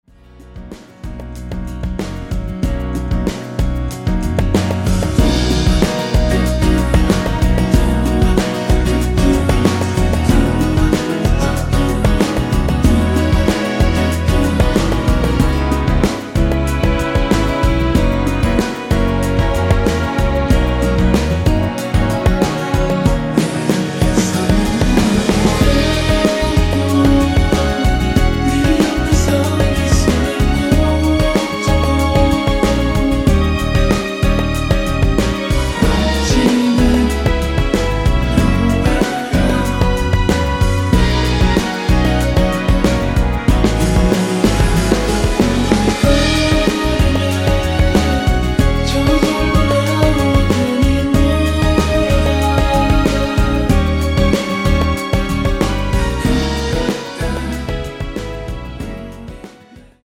◈ 곡명 옆 (-1)은 반음 내림, (+1)은 반음 올림 입니다.